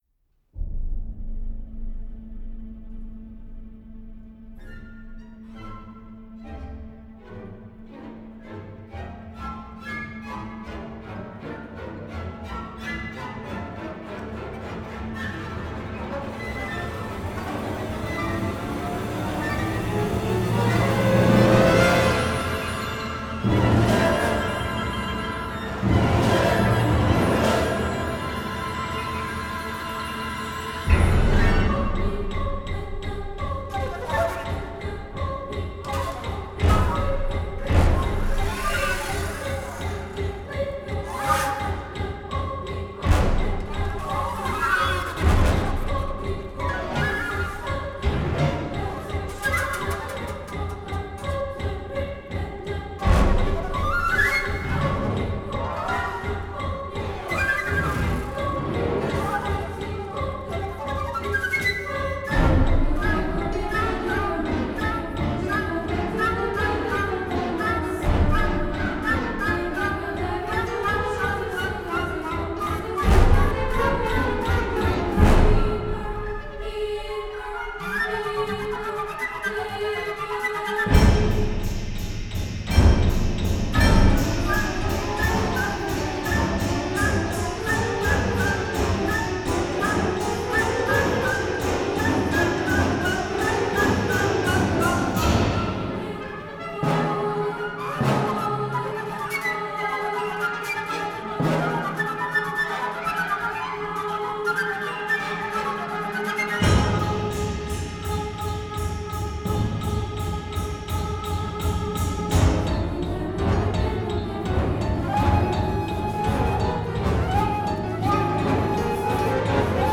FLUTE CONCERTO